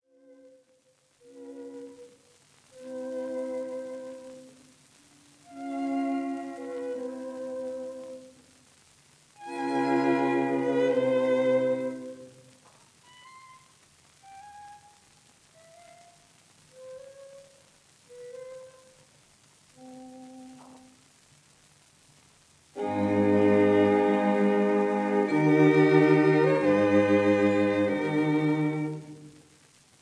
violins
viola
cello
in F minor — Largo